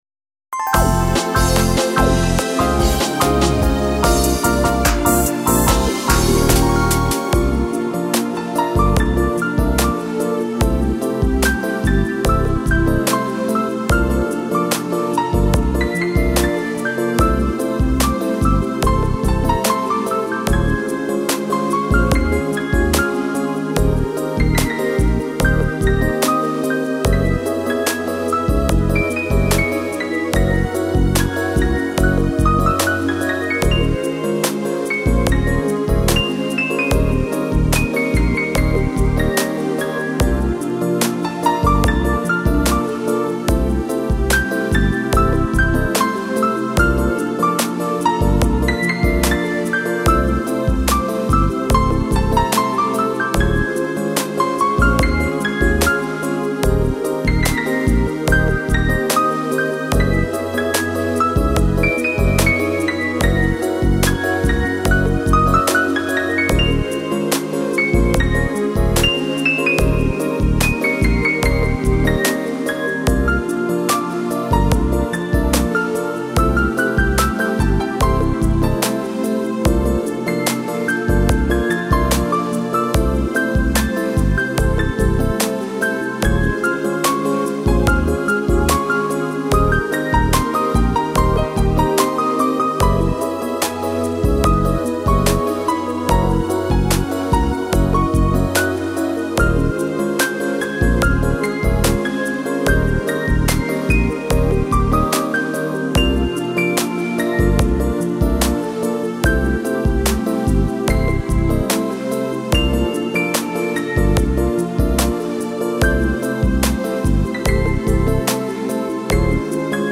よろしければBGMにどうぞ(笑)
さざ波。ウミネコ達の鳴声。潮風。遠浅の碧い海…。